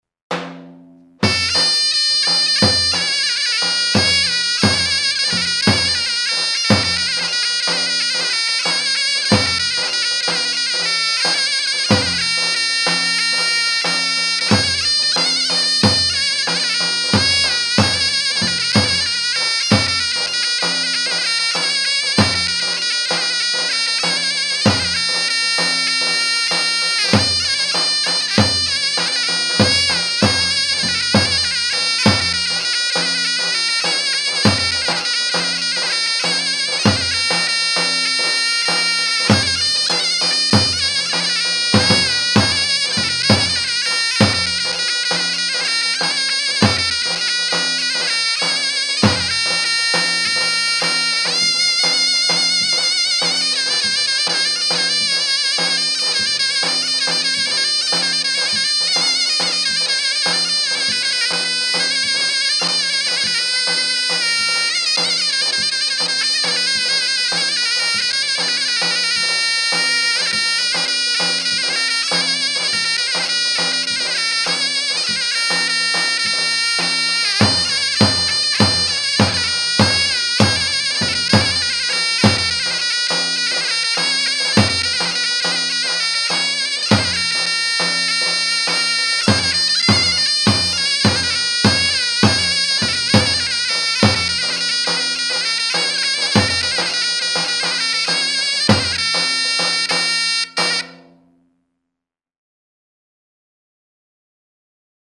Kategori Sözlü, Sözsüz Yöresel Müzikler